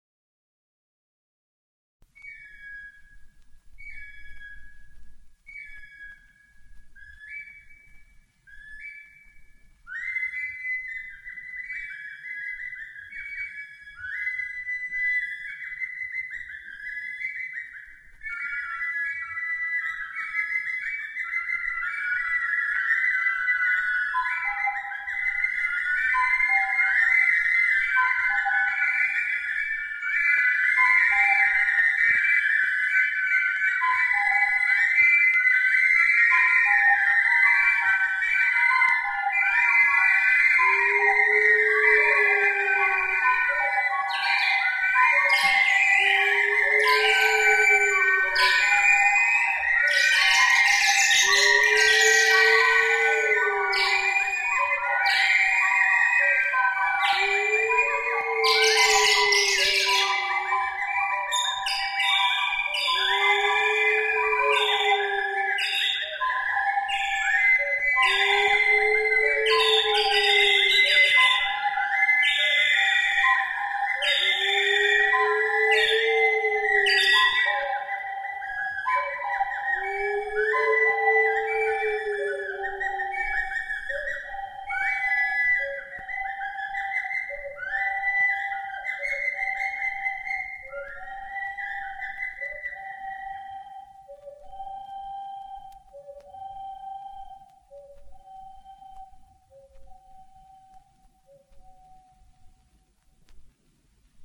The sound of a molinukas is soft, and the tembre is delicate.
Melodies are improvised.
Molinukai: imitation of bird's voices, instrumental group, RATILIO, 1982 - [